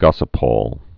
(gŏsə-pôl, -pōl, -pŏl)